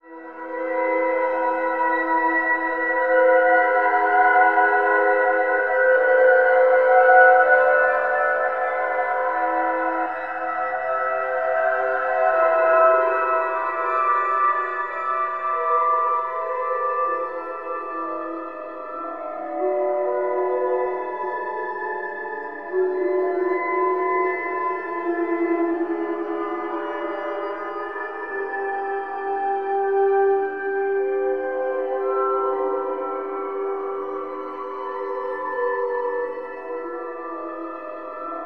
61.1FLUTE1.wav